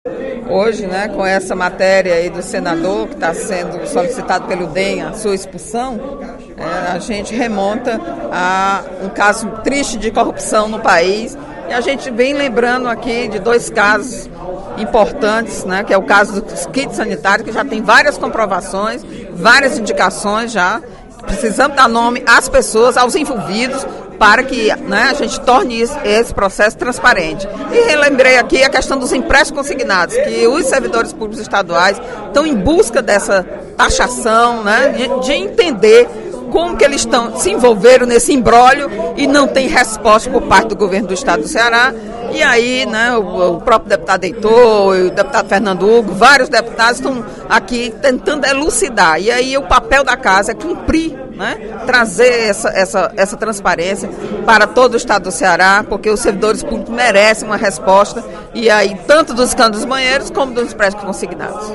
O pronunciamento da parlamentar foi motivado pela denúncia de quebra de decoro parlamentar que recaiu sobre o senador Demóstenes Torres (DEM-GO).